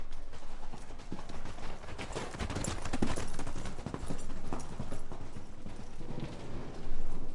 牧场之声 " 反向马的脚步声在碎石泥地上的声音 01
描述：我把马蹄的记录放在砾石上并翻转以获得不同的弹出纹理。
标签： 反转 步骤
声道立体声